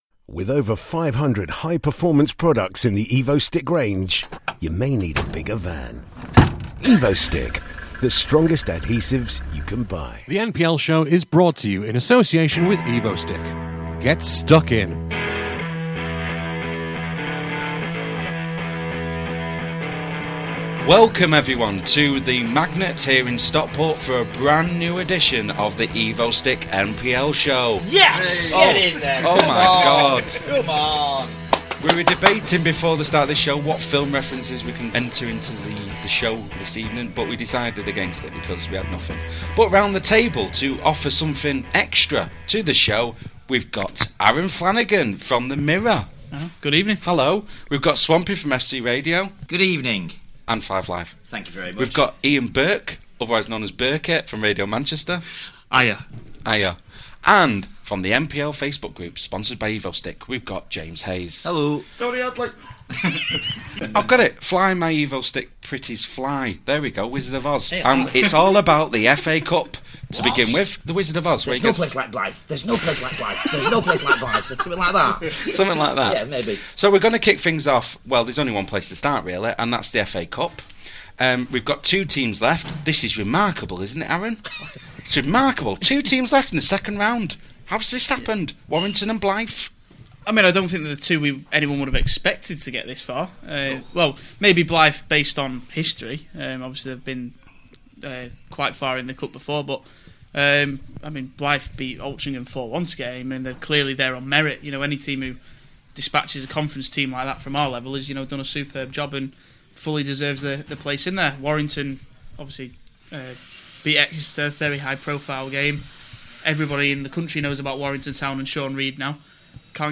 With Thanks: This programme was recorded at the Magnet in Stockport on Thursday 4th December 2014.